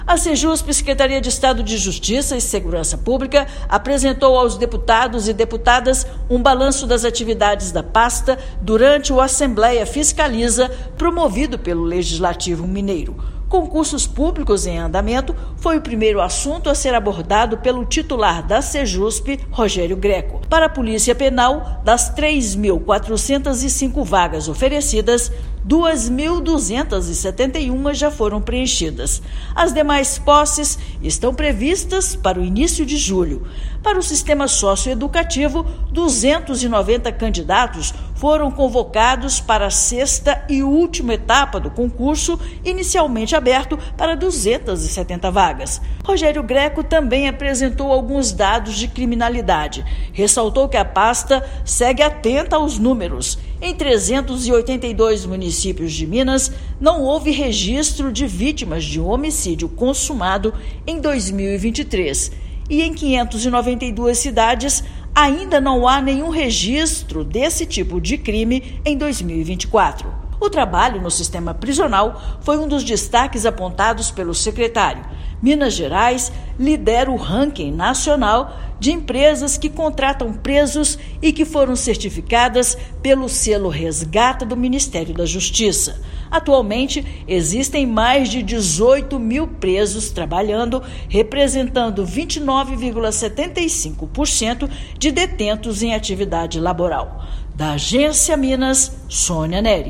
Pasta destacou avanços e esclareceu as dúvidas dos deputados estaduais. Ouça matéria de rádio.